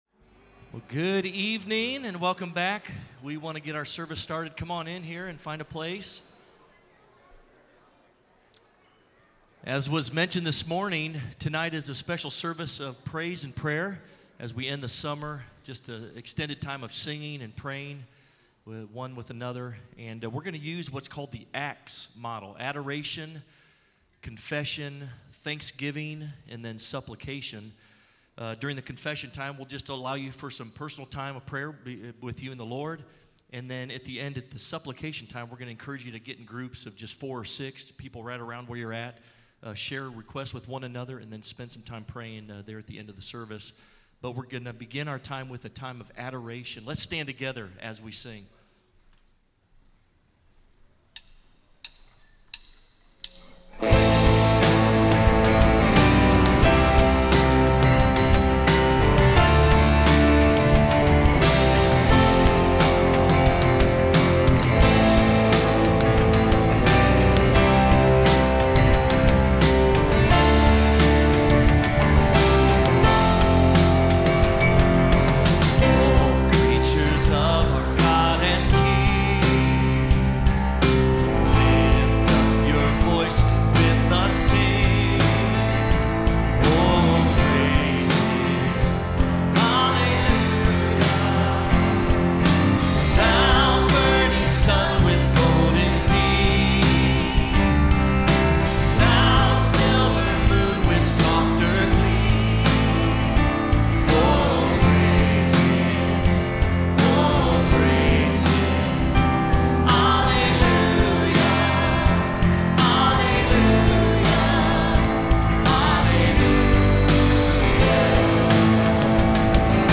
Audio Praise & Prayer Night 2025-08-31 pm Worship Services Praise & Prayer Services Audio Video ◀ Prev Series List Previous 2.